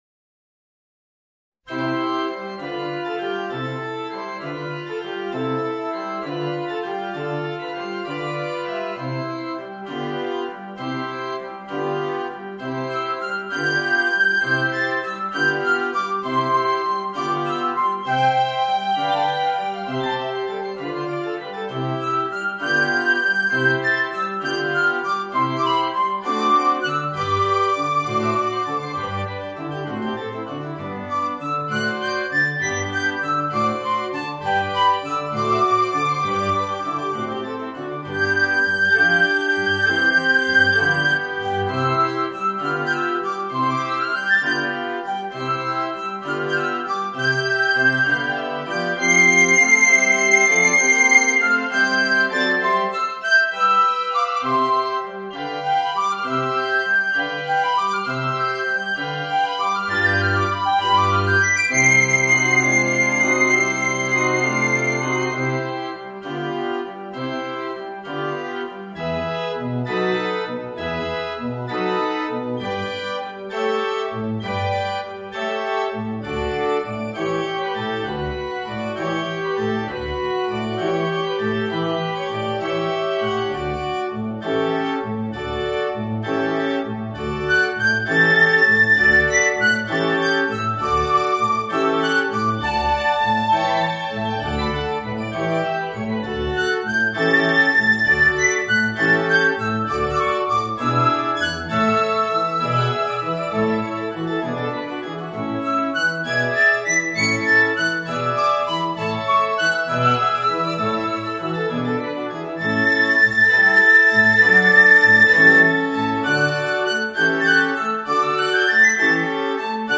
Voicing: Piccolo and Organ